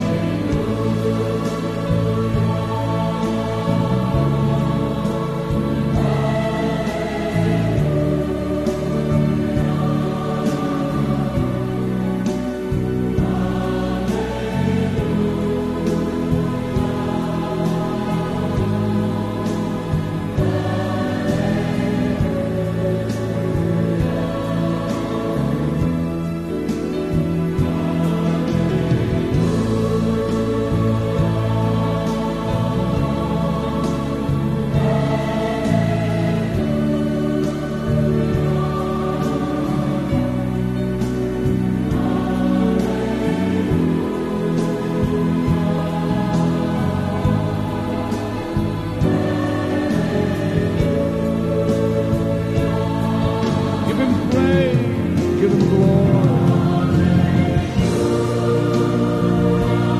Sound of firecrackers. sound effects free download